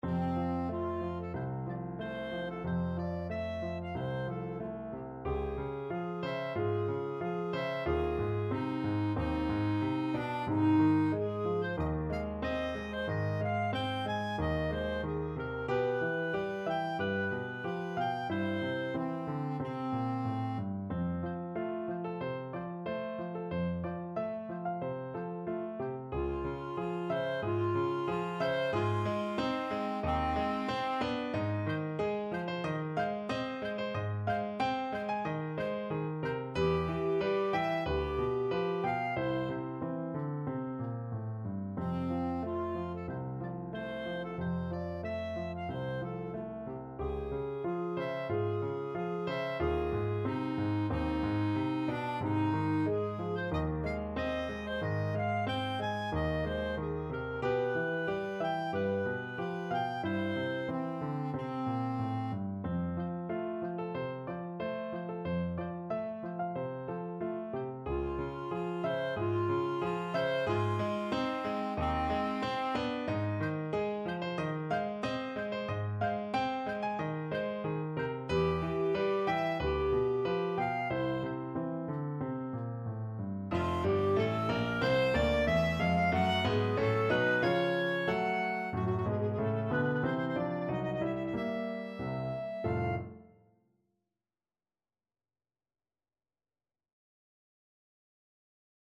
Clarinet
F major (Sounding Pitch) G major (Clarinet in Bb) (View more F major Music for Clarinet )
Andantino =92 (View more music marked Andantino)
4/4 (View more 4/4 Music)
Classical (View more Classical Clarinet Music)
prokofiev_peter_and_wolf_CL.mp3